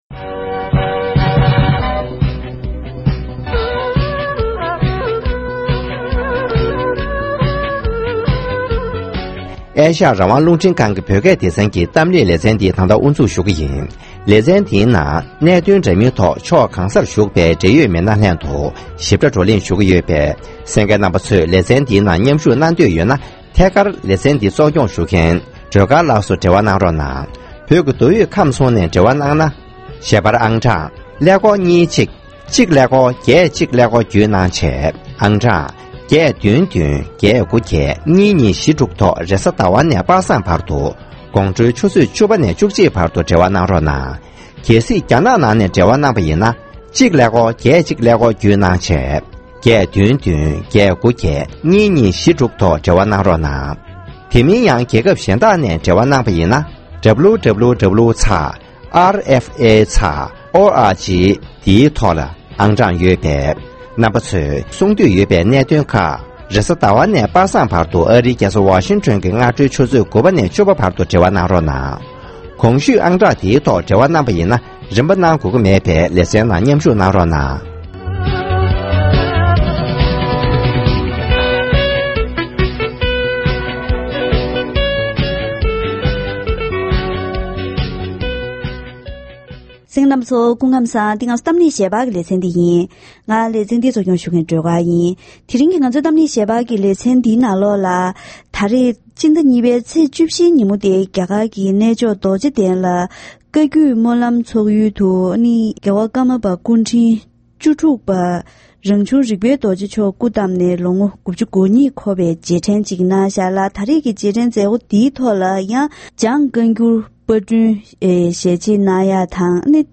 ༄༅། །དེ་རིང་གི་གཏམ་གླེང་ཞལ་པར་ལེ་ཚན་ནང་བཀའ་བརྒྱུད་སྨོན་ལམ་ཚོགས་ཡུལ་གནས་མཆོག་རྡོ་རྗེ་གདན་ལ་རྒྱལ་དབང་ཀརྨ་པ་སྐུ་ཕྲེང་བཅུ་དྲུག་ལ་རྗེས་དྲན་གནང་བའི་ཐོག་འཇང་བཀའ་འགྱུར་བསྐྱར་པར་གྱིས་དབུ་འབྱེད་གནང་སྐབས་དཔལ་རྒྱལ་དབང་ཀརྨ་པ་རིན་པ་ཆེ་མཆོག་དང་འབྲི་གུང་སྐྱབས་མགོན་ཆེ་ཚང་རིན་པོ་ཆེ་མཆོག་ནས་དམིགས་བསལ་གསུང་བཤད་གནང་བའི་སྐོར་ཞིབ་ཕྲ་ངོ་སྤྲོད་ཞུས་པ་ཞིག་གསན་རོགས་གནང་།